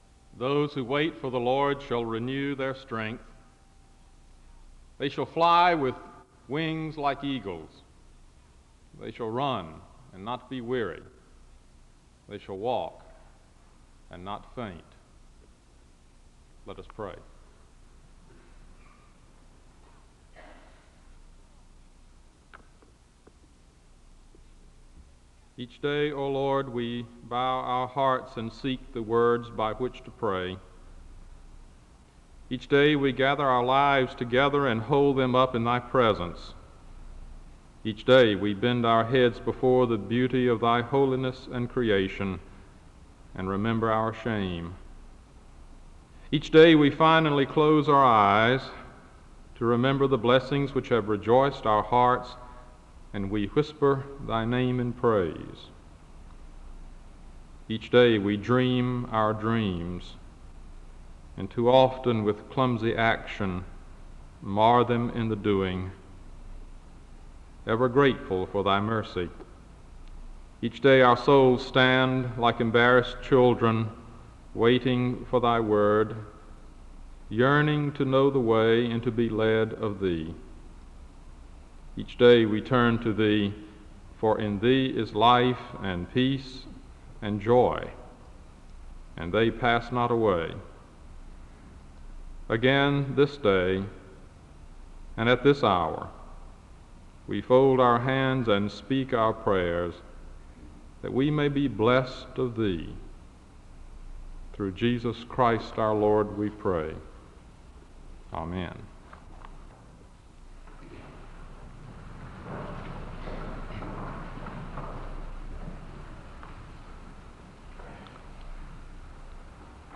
The sermon begins with a reading from Isaiah and a word of prayer (00:00-01:50). The speaker gives two words of community concerns, and another speaker gives a brief word about God’s love for mankind (01:51-05:24). A woman sings a song of worship (05:25-08:06). The audience is led in a responsive prayer (08:07-10:24). A woman sings the song, “The Old Rugged Cross” (10:25-14:34).
The service ends with a benediction (19:11-19:34).
Public worship--Christianity